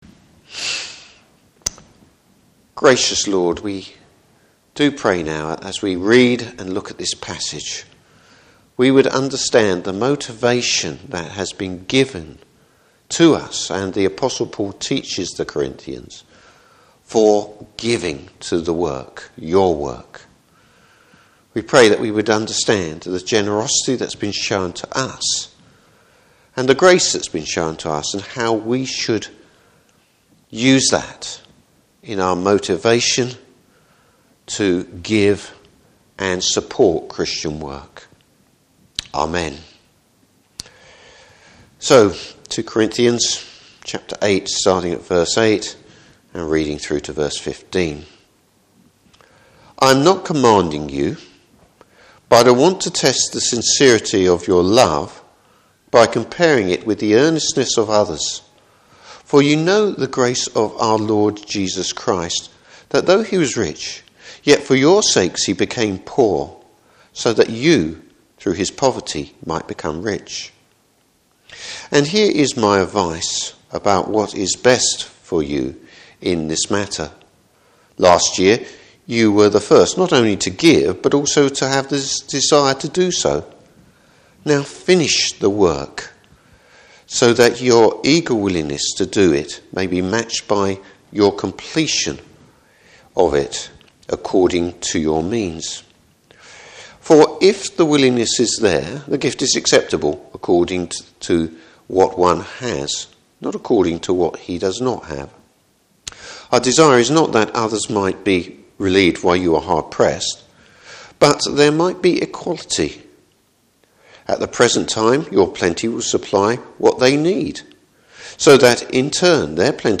Service Type: Morning Service Paul’s advice when it comes to giving.